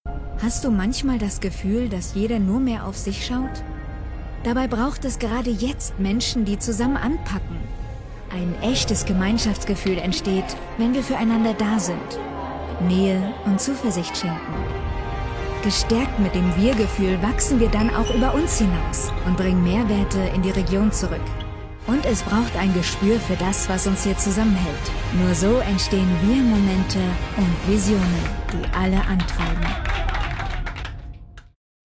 Imagefilm Raiffeisenbank Warm, episch, jung, gefühlvoll
Presentation